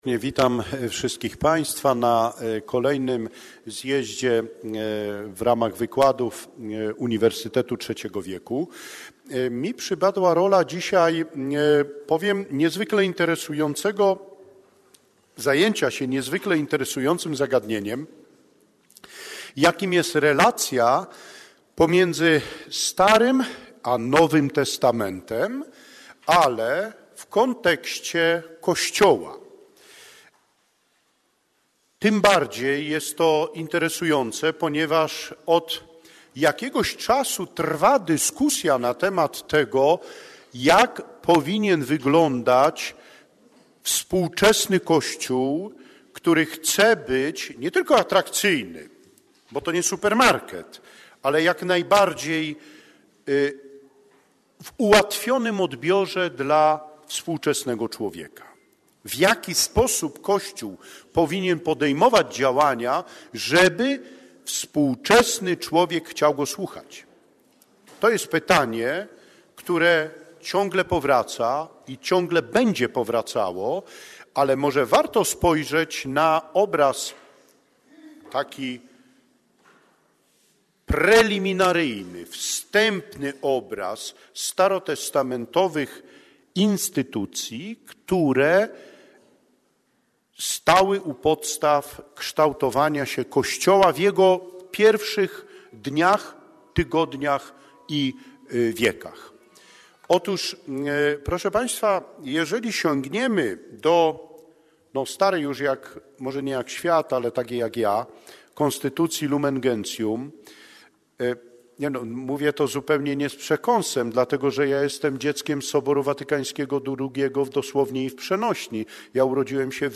Wykłady o godz. 10:15 w Auli PWT, odbyły się wg. planu: